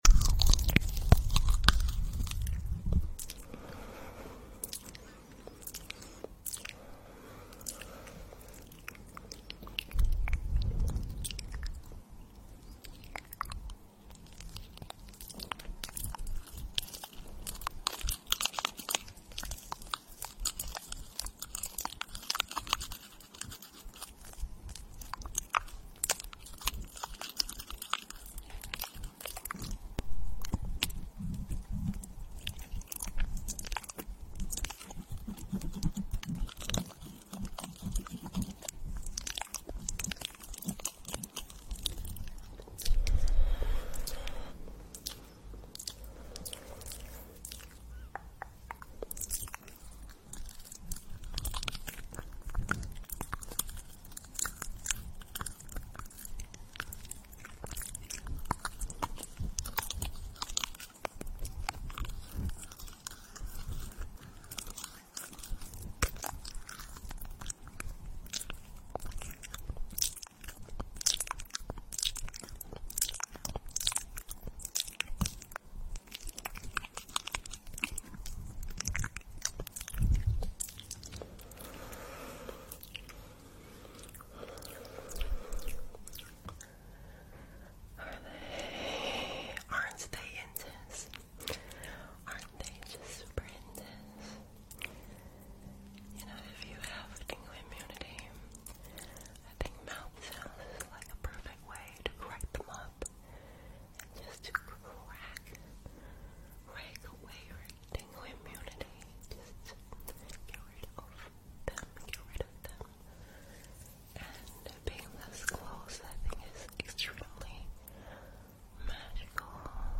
Full Mouthsounds 👄